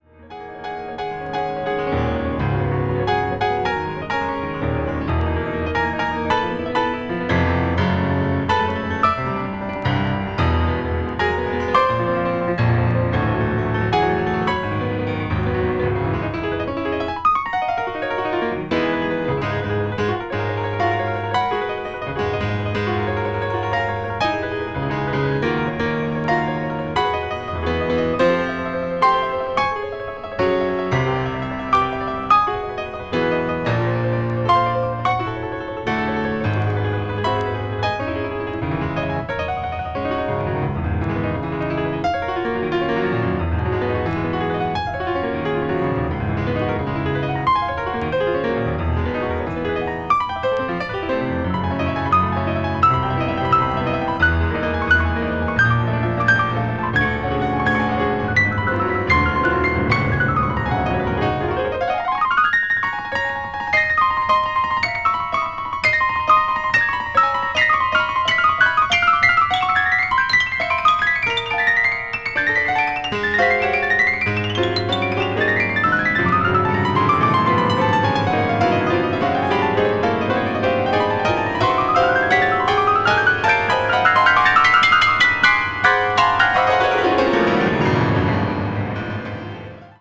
アコースティック・ピアノのソロ作品ですが、あなどるなかれ、物凄いことになってます。
星屑が降り注ぐかのように紡ぎだされるオーガニックな旋律があまりにも素晴らしいです。